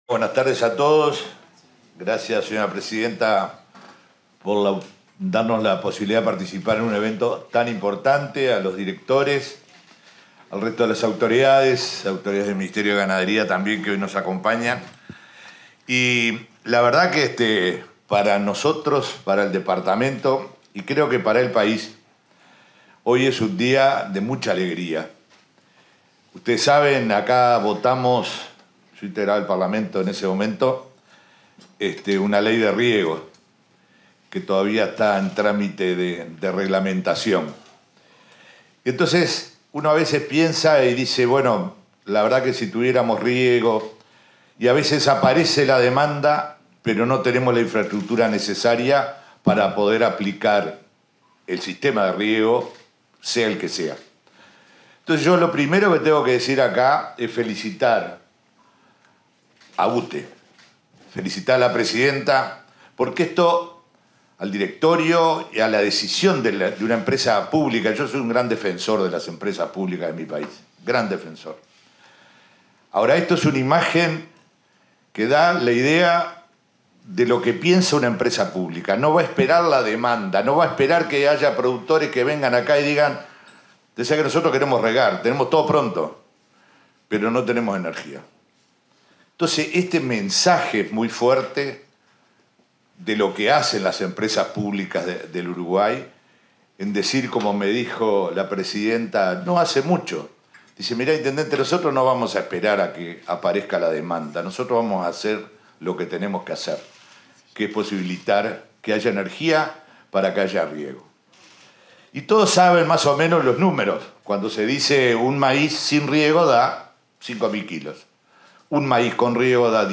Palabras de la presidenta de UTE y del intendente de Río Negro
En el marco de la presentación del proyecto piloto de riego productivo y de la apertura de la licitación asociada en el departamento de Río Negro,